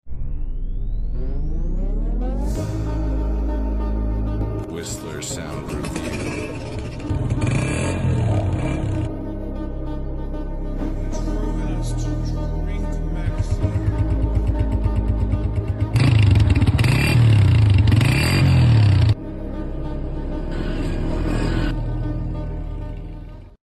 Customer Review from Rawalpindi sound effects free download